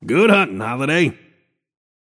Shopkeeper voice line - Good huntin‘, Holliday.